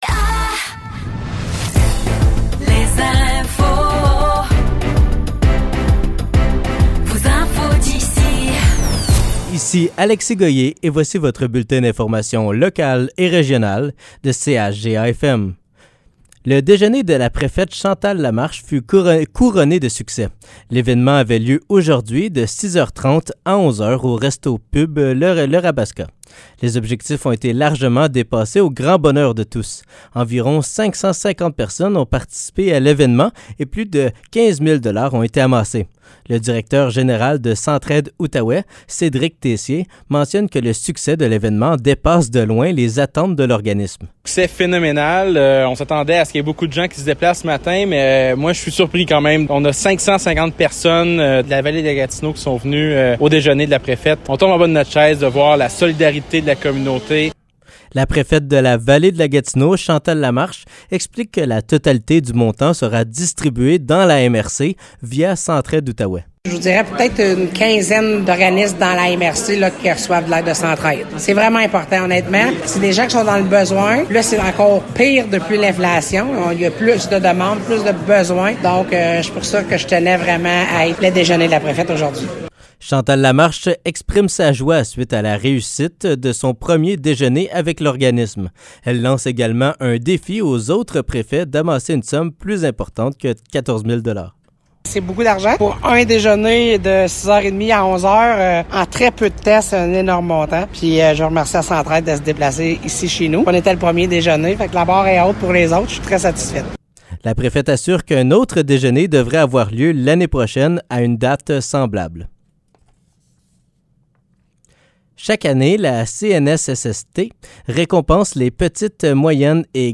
Nouvelles locales - 21 septembre 2023 - 15 h